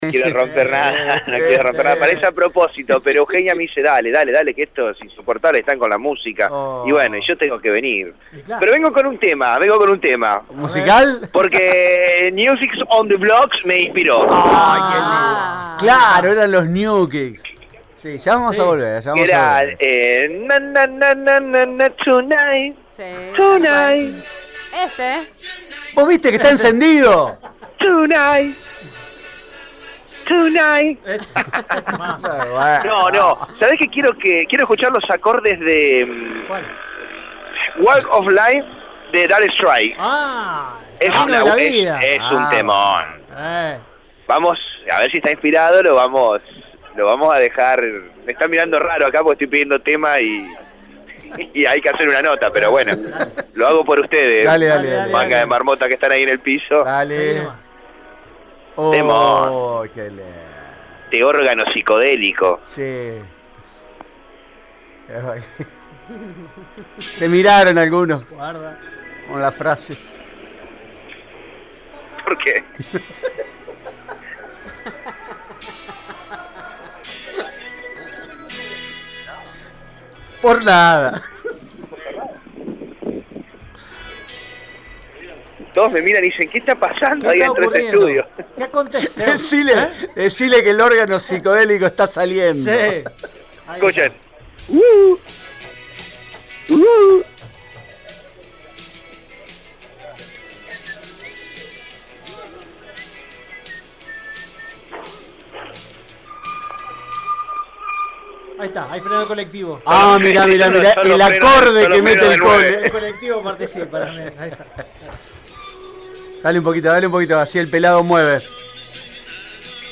Radio